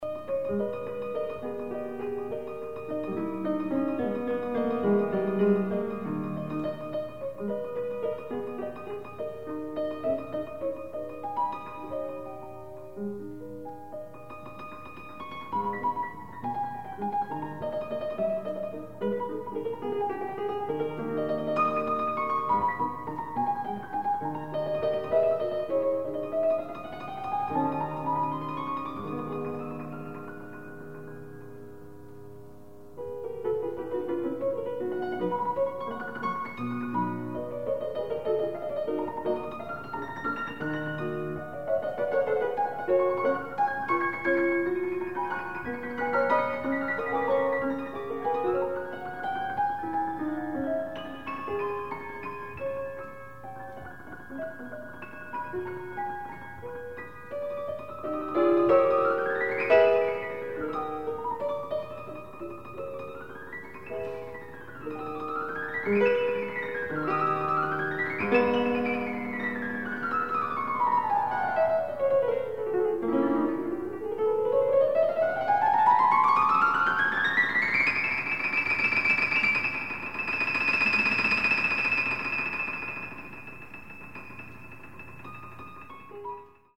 Additional Date(s)Recorded September 15, 1977 in the Ed Landreth Hall, Texas Christian University, Fort Worth, Texas
Etudes
Short audio samples from performance